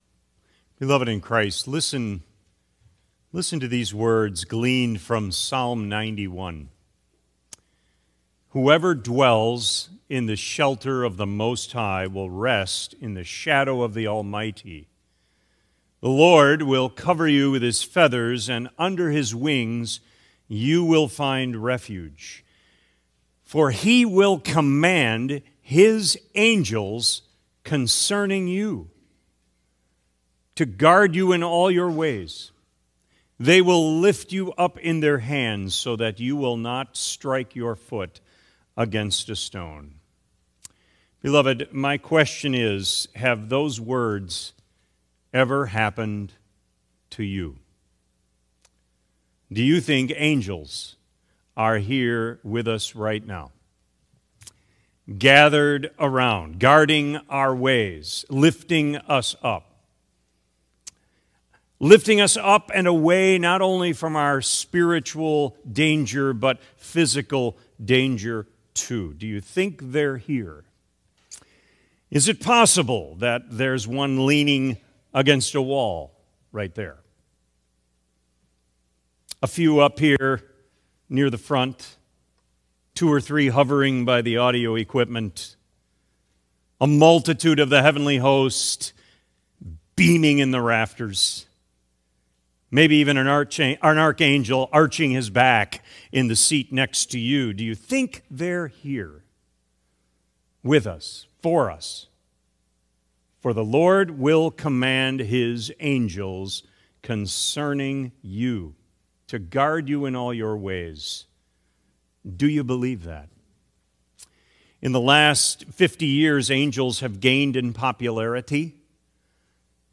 Sermon Recordings | Faith Community Christian Reformed Church
“Angels Watching Over Me” December 14 2025 A.M. Service